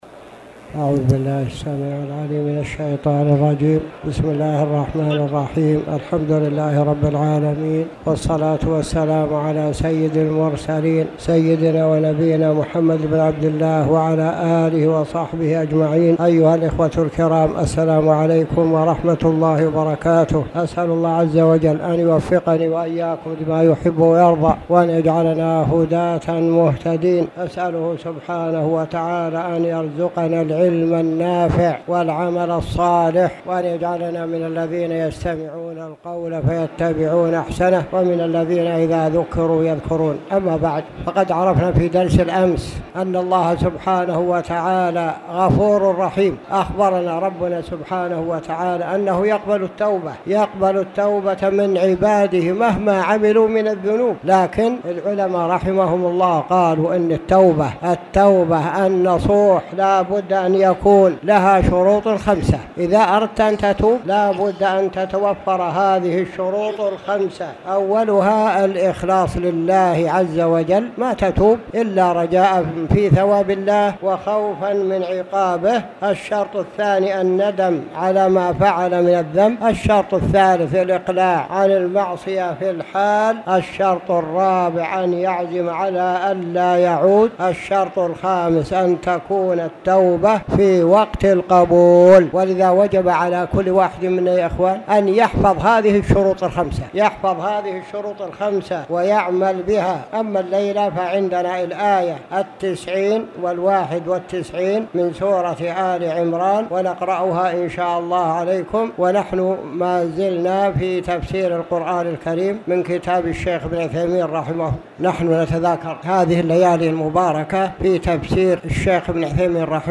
تاريخ النشر ٢٦ رمضان ١٤٣٨ هـ المكان: المسجد الحرام الشيخ